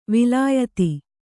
♪ vilāyati